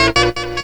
HORN STUT05R.wav